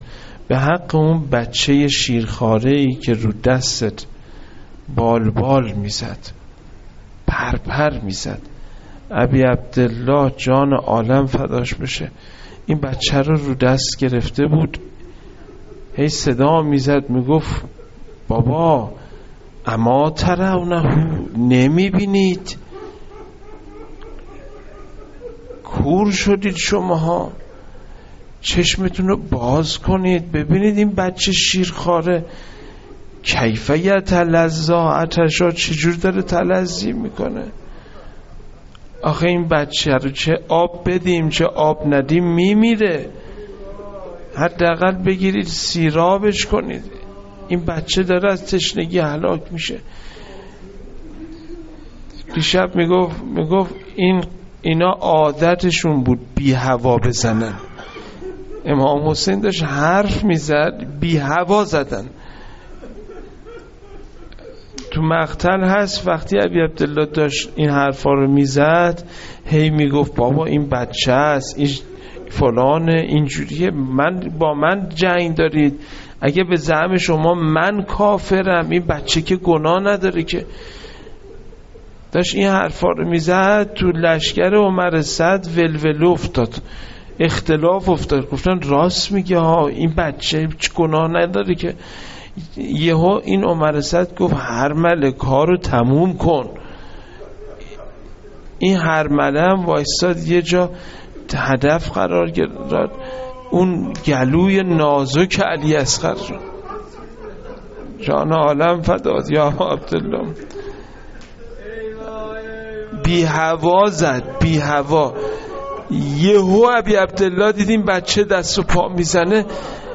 روضه جانسوز حضرت علی اصغر علیه السلام
روضه-جانسوز-حضرت-علی-اصغر-علیه-السلام-.mp3